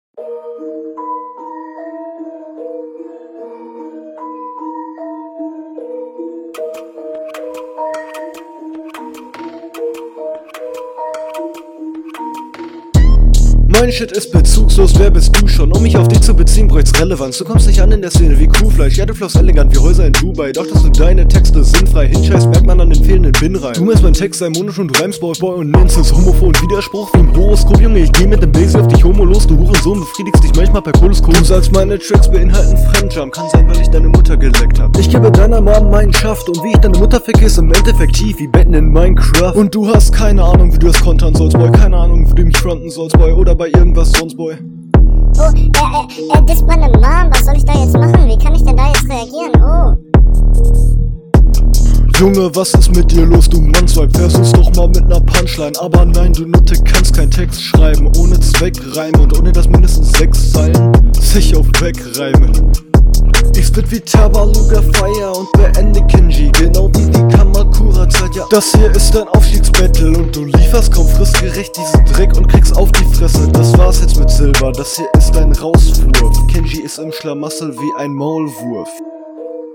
hast zwischendurch mal ganz nice flowansätze, grade am anfang, aber lässt zum ende immer mehr …
Also Soundtechnisch hat es sich verbessert, aber man versteht Teilweise noch weniger als in der …